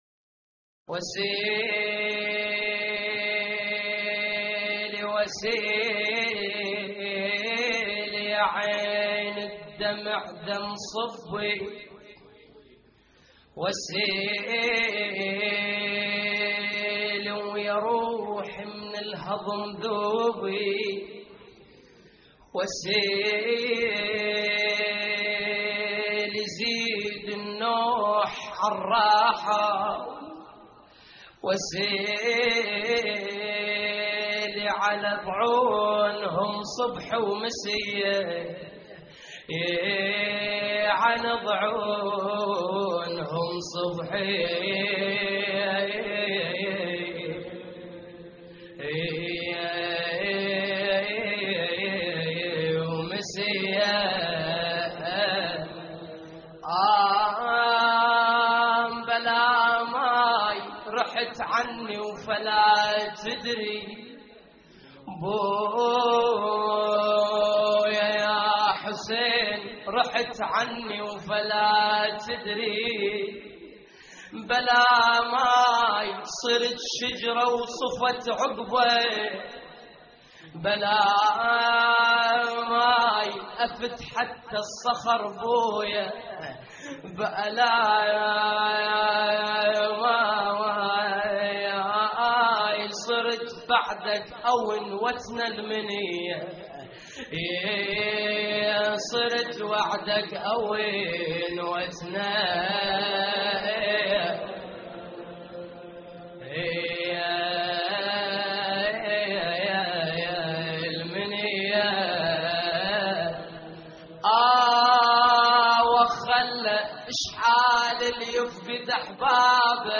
اللطميات الحسينية
موقع يا حسين : اللطميات الحسينية وسيلي يا عيني الدمع دم صبي وسيلي (نعي) - 3محرم1426هـ لحفظ الملف في مجلد خاص اضغط بالزر الأيمن هنا ثم اختر (حفظ الهدف باسم - Save Target As) واختر المكان المناسب